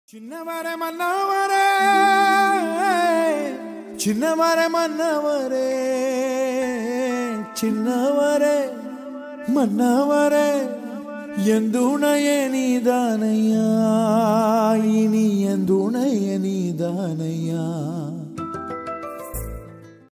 best flute ringtone download | love song ringtone
melody ringtone